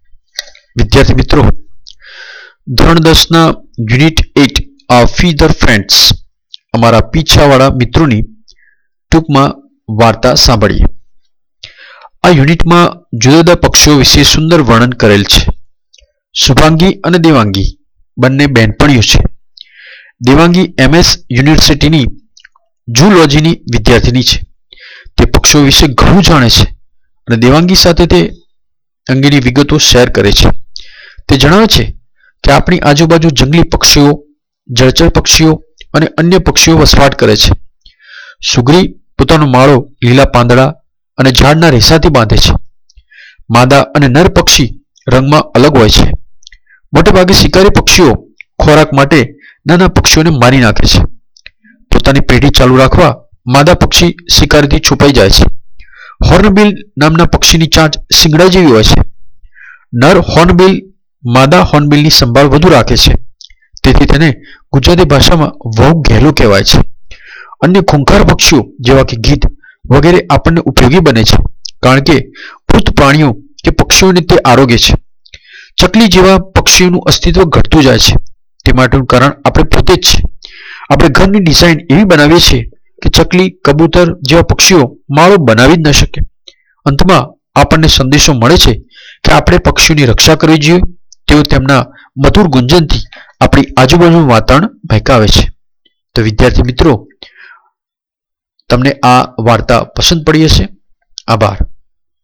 Std.10 Audiobook ( Our Feathered Friends Short Story)